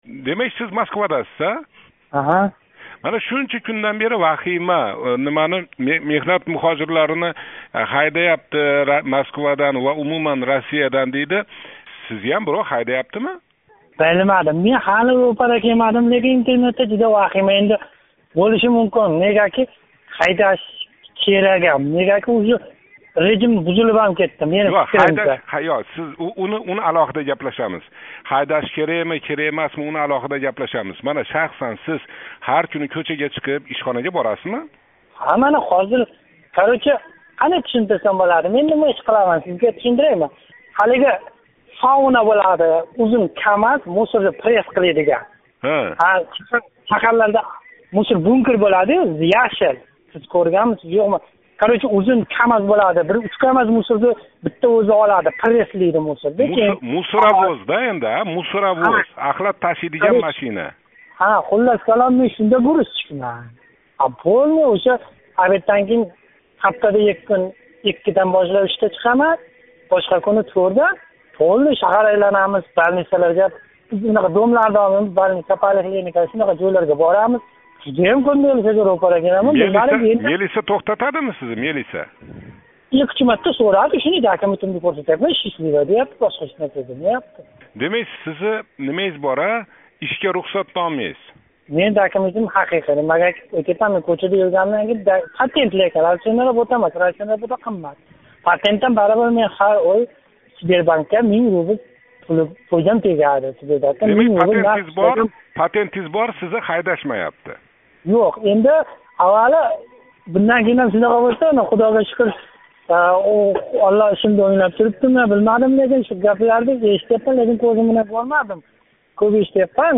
Муҳожир йигит билан суҳбат